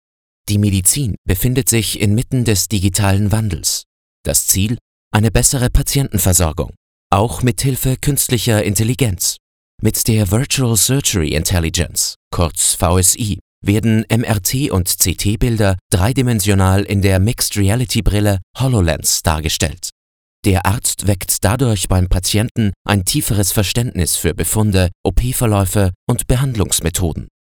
Klangfarben: ruhig, smart, kräftig, dynamisch aber auch gerne schrill, kratzig und frech.
Sprechprobe: eLearning (Muttersprache):
Erklärfilm HoloLens.mp3